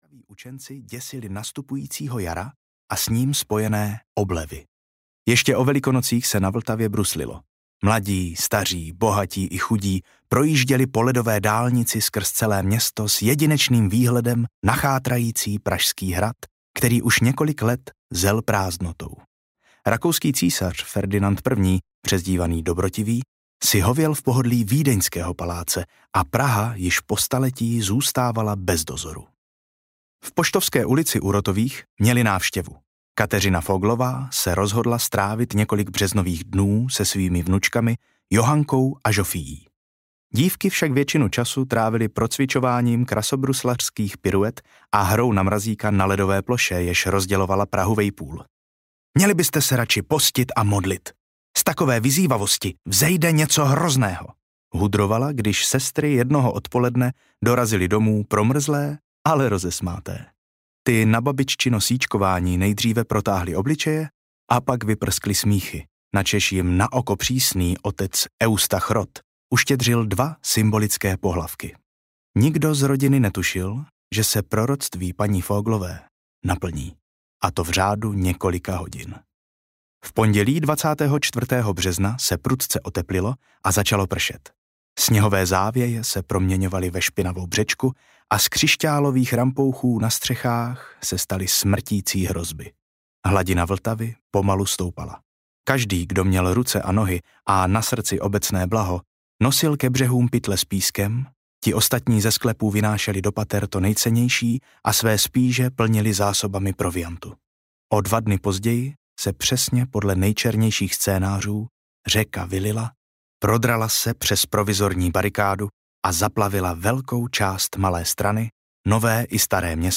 Národní opruzení audiokniha
Ukázka z knihy